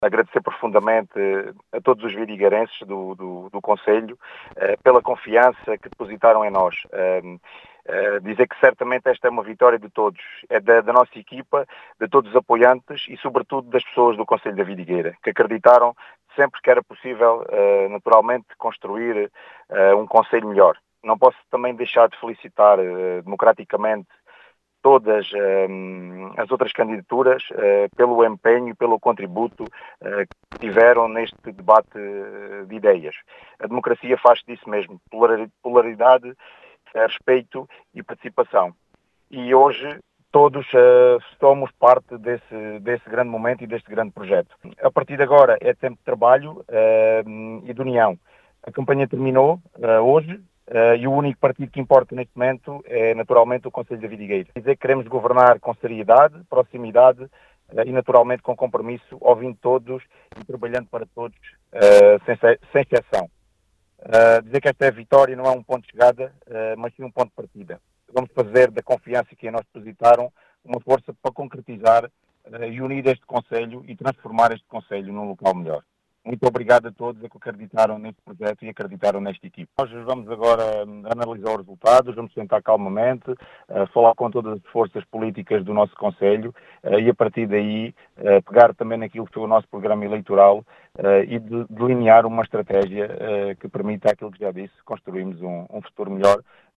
Em declarações à Rádio Vidigueira, Ricardo Bonito, candidato do PS, diz ser “tempo de trabalho e união” querendo “trabalhar para todos sem exceção”.